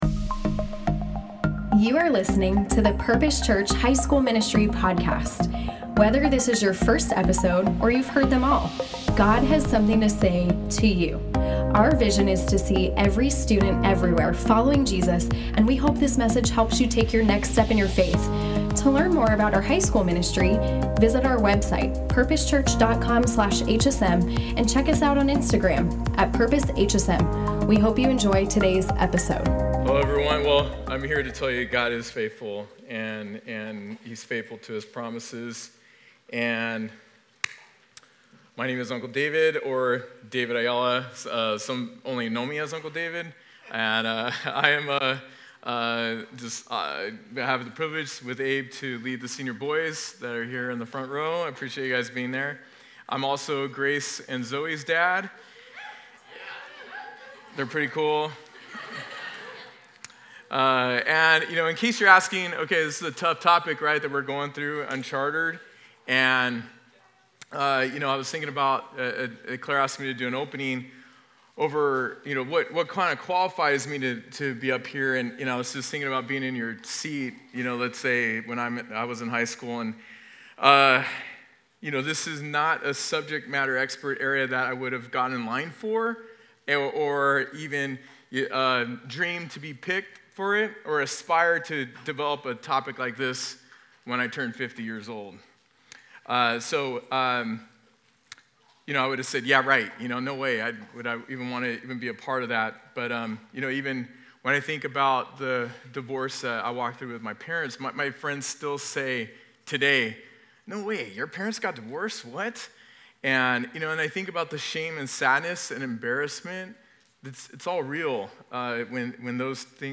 Q&A Panel | Uncharted: Crucial Conversations About Life’s Biggest Storms